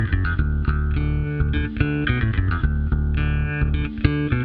标签： male_vocals ambient bass guitar female_vocals drums
声道立体声